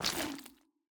latest / assets / minecraft / sounds / block / sculk / step6.ogg
step6.ogg